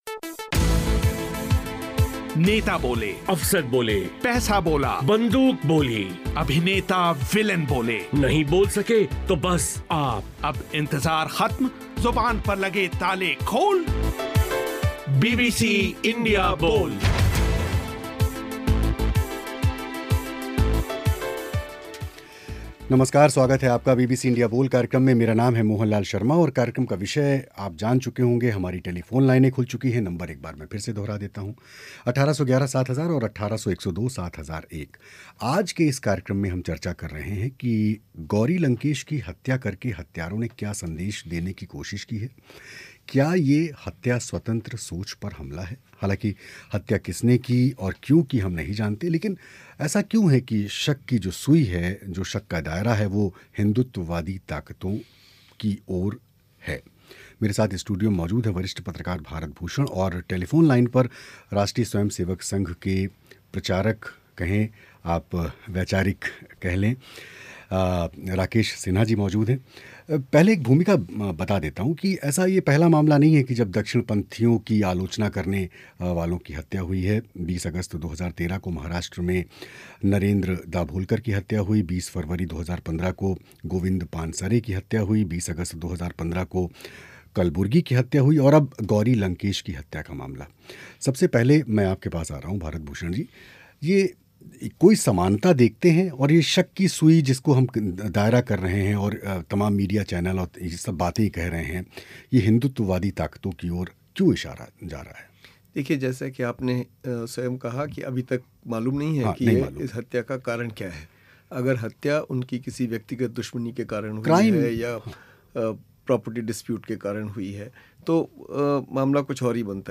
इंडिया बोल में चर्चा इसी विषय पर हुई.